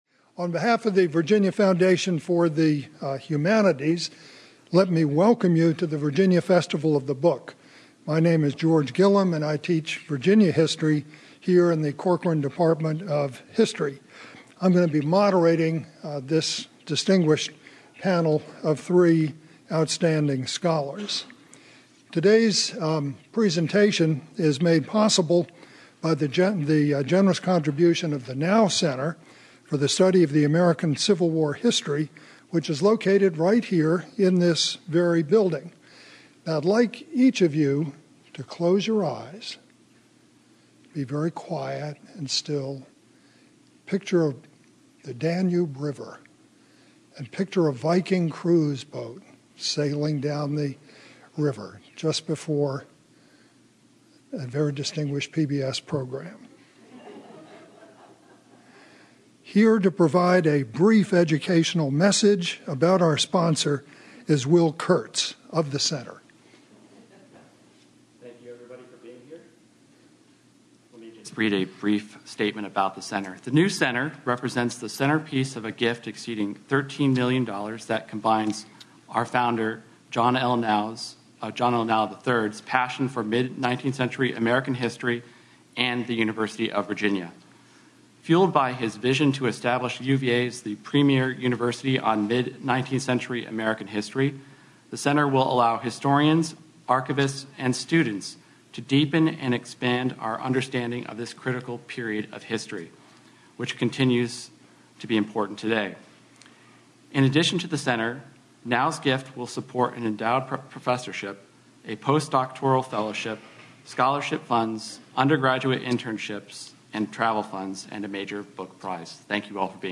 Following the presentation questions were taken from the audience.